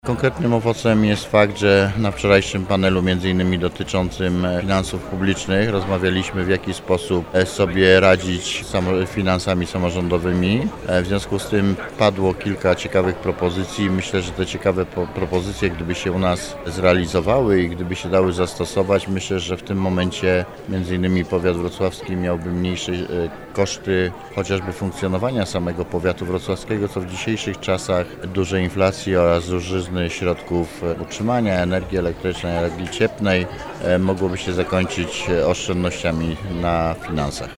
-W trakcie wydarzenia padło kilka ciekawych propozycji, dodaje starosta.